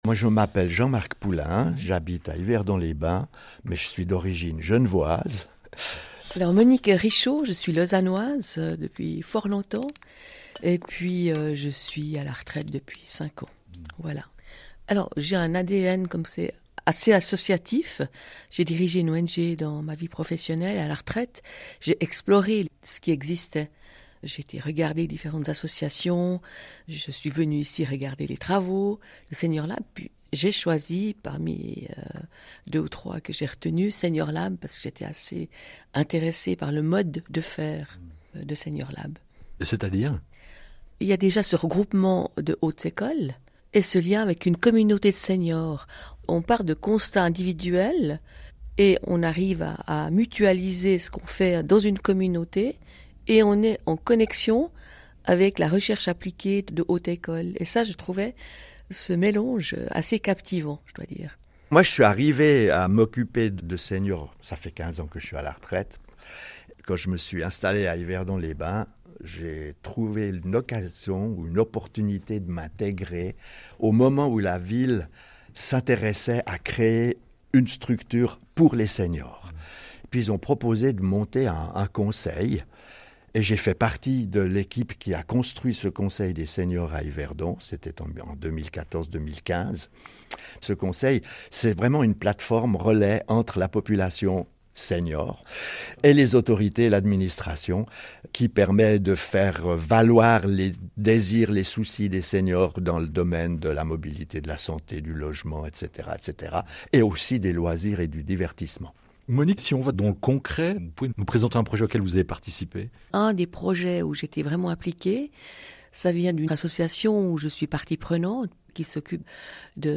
Extrait de l'émission CQFD de la RTS - Décembre 2024